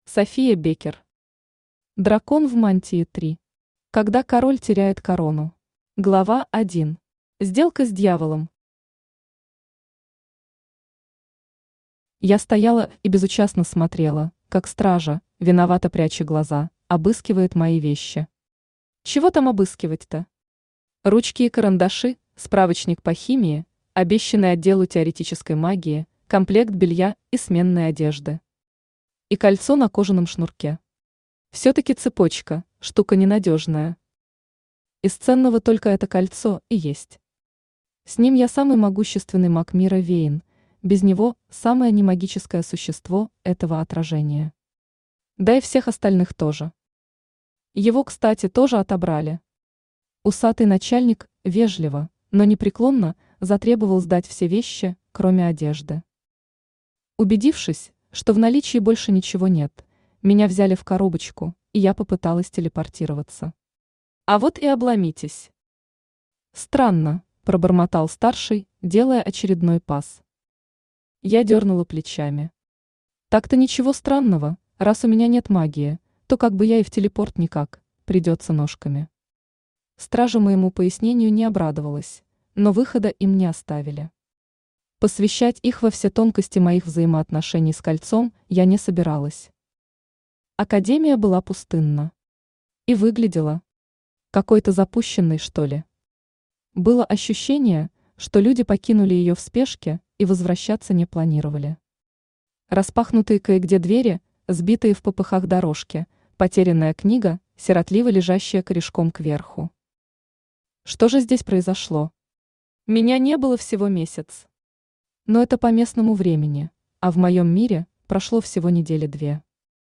Аудиокнига Дракон в мантии 3. Когда король теряет корону | Библиотека аудиокниг
Когда король теряет корону Автор София Беккер Читает аудиокнигу Авточтец ЛитРес.